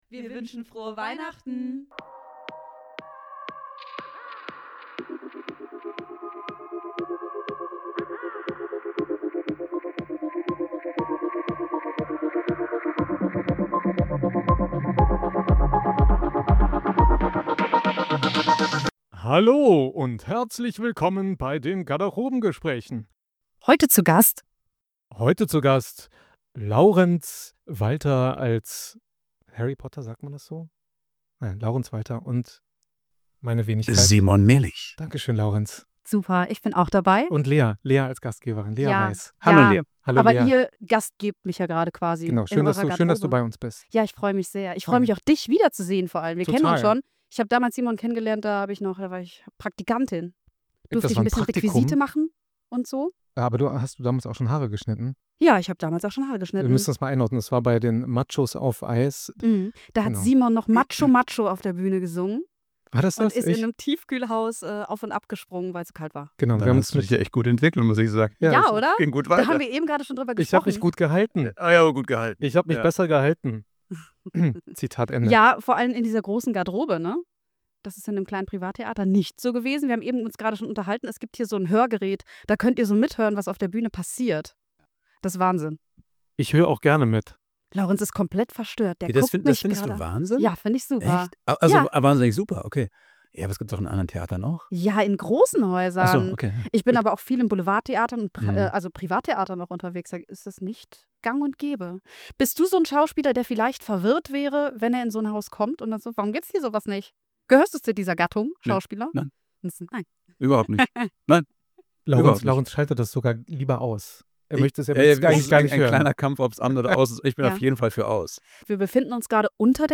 In ruhiger, persönlicher Atmosphäre sprechen sie über ihre Rollen, die besondere Dynamik zwischen Harry und Draco, das Spielen in einer der erfolgreichsten Theaterproduktionen der Welt und darüber, was hinter der Bühne passiert, wenn der Vorhang fällt. Eine Folge voller ehrlicher Einblicke, Nähe und echter Backstage-Momente aus der magischen Welt von Harry Potter und das verwunschene Kind.